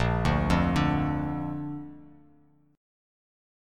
Listen to A# strummed